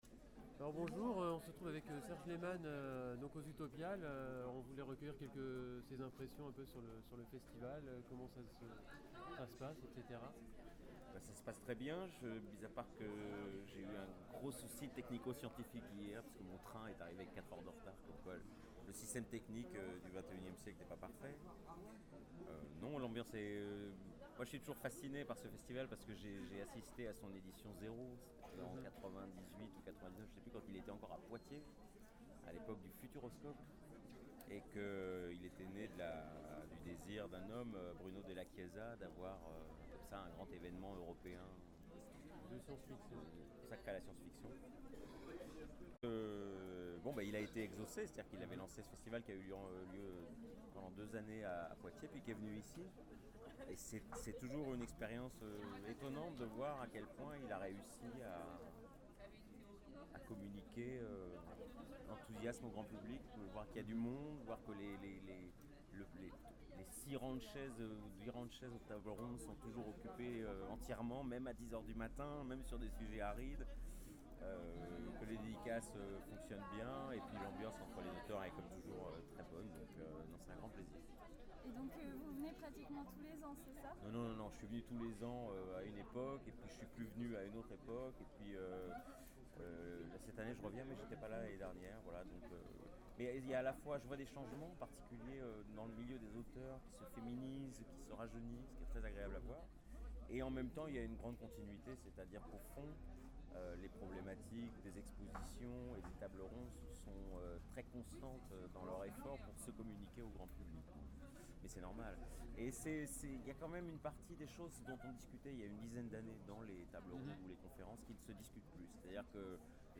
Interview Serge Lehman